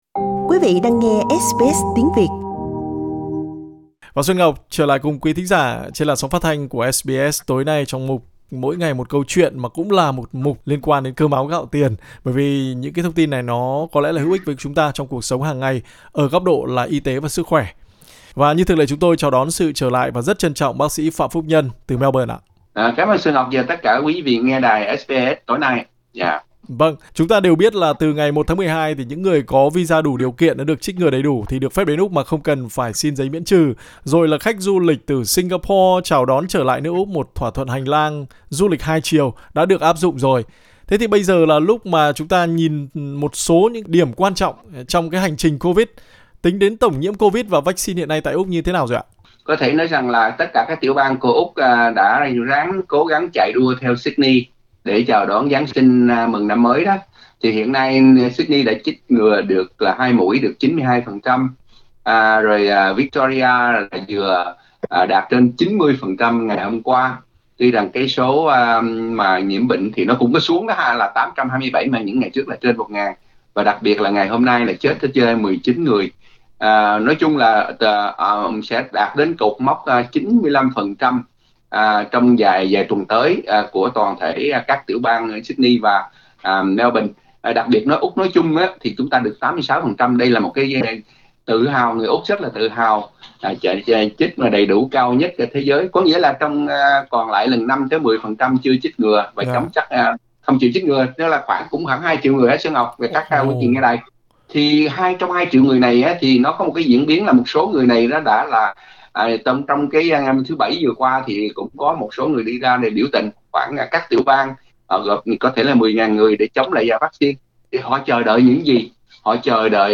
Trong cuộc phỏng vấn với SBS Tiếng Việt